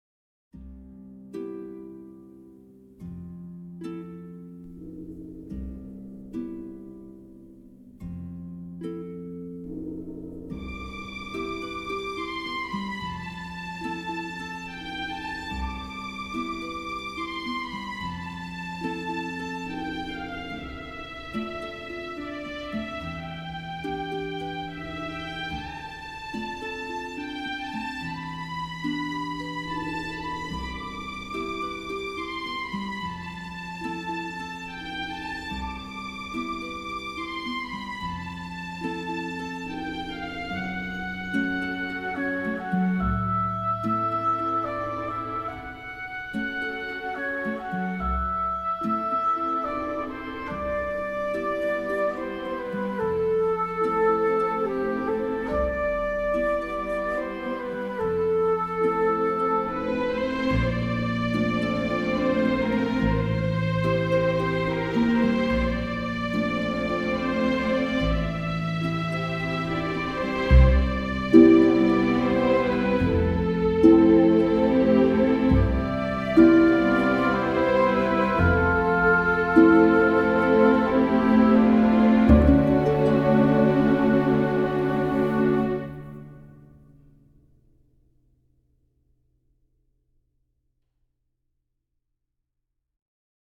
Van de stukken voor orkest is er met behulp van StaffPad een synthetische "weergave" worden gemaakt.
Op.3 No.3 Hommage à Satie Symfonieorkest augustus 2025 Fluit, hobo, hoorn, harp, strijkers en cymbaal